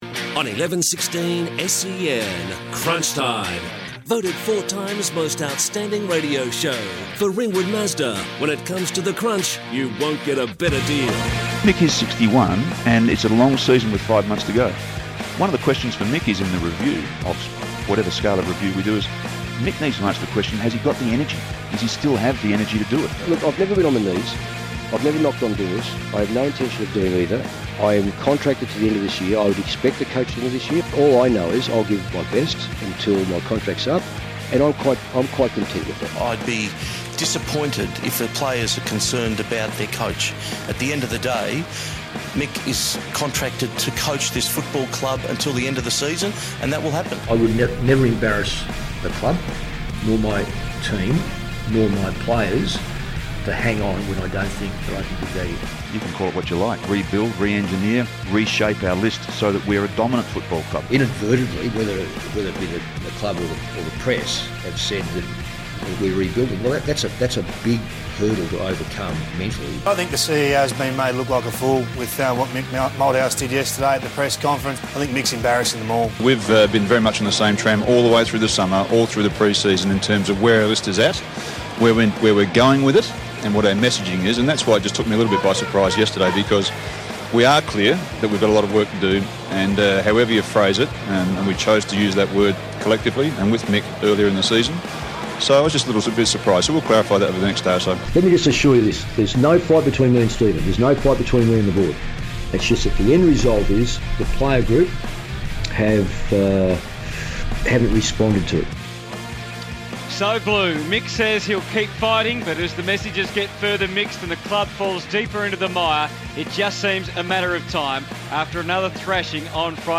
Crunch Time Segment Segment One: Carlton discussion including Tom Lonergan interview